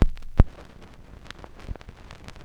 Record Noises
Record_End_1.aif